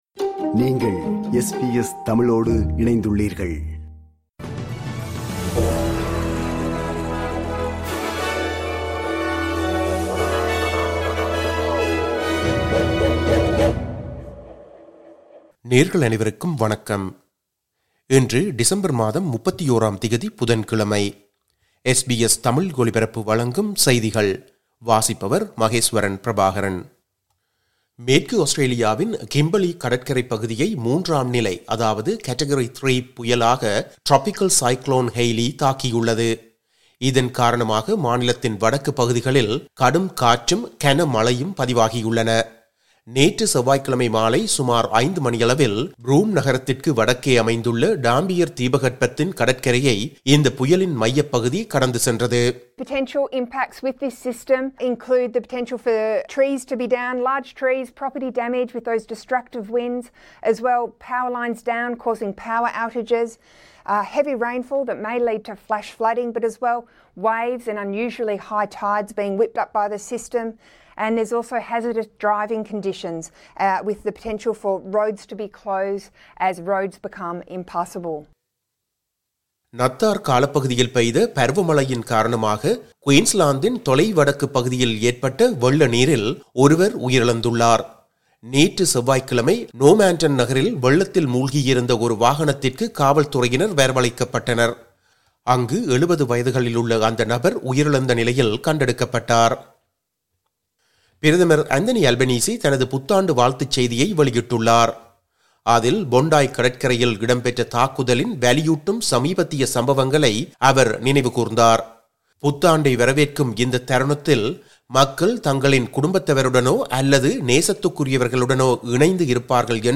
இன்றைய செய்திகள்: 31 டிசம்பர் 2025 புதன்கிழமை
SBS தமிழ் ஒலிபரப்பின் இன்றைய (புதன்கிழமை 31/12/2025) செய்திகள்.